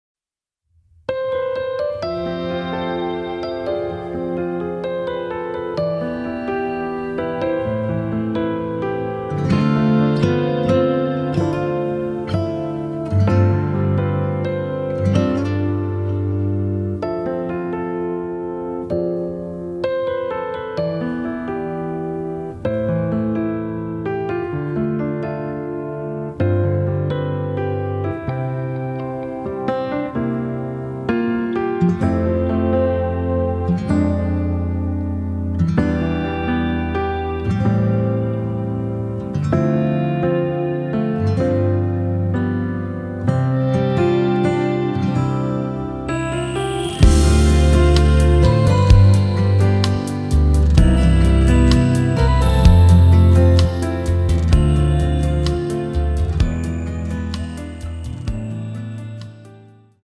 [공식 음원 MR] 입니다.
앞부분30초, 뒷부분30초씩 편집해서 올려 드리고 있습니다.
중간에 음이 끈어지고 다시 나오는 이유는